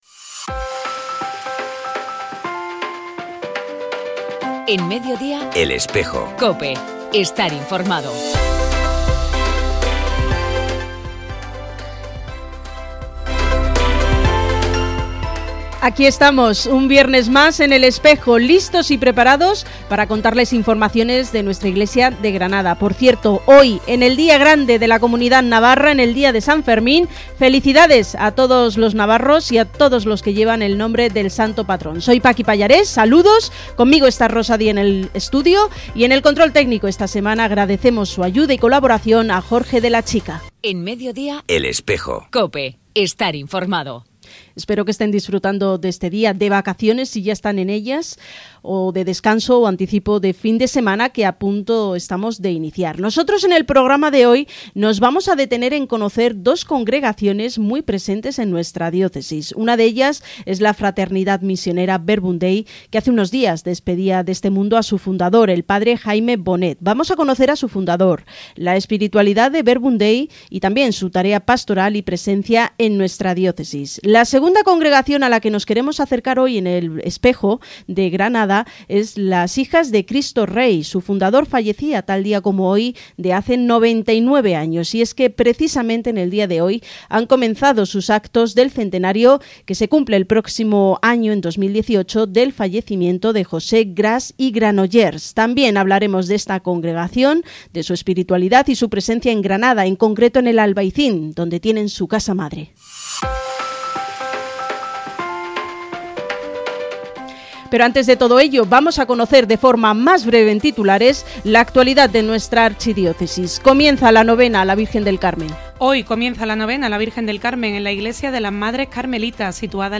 En este programa de “El Espejo”, emitido en COPE Granada el viernes 7 de julio y realizado por el Secretariado de Medios de Comunicación del Arzobispado de Granada, les ofrecemos dos interesantes temas de actualidad.
En segundo lugar, les ofrecemos un reportaje sobre el fundador de las Hijas de Cristo Rey, Venerable José Gras y Granollers, quien vivió y murió en Granada.